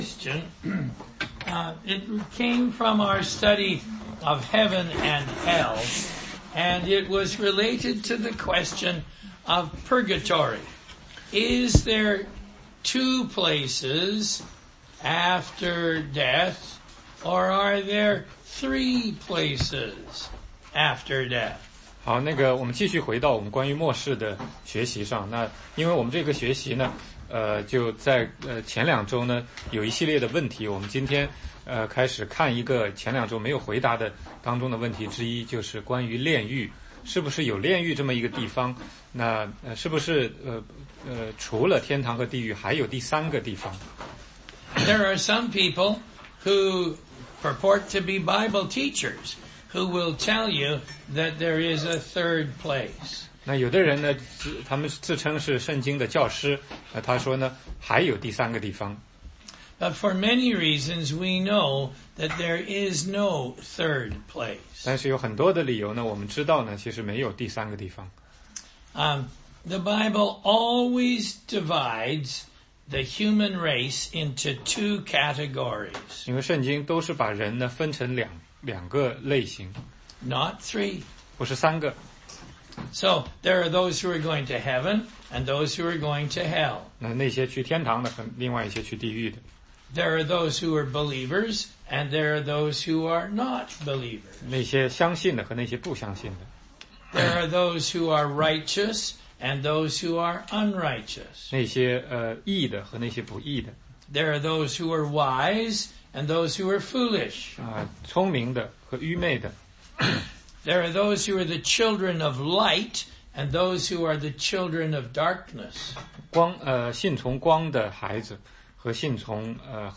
16街讲道录音 - 耶稣降生的预备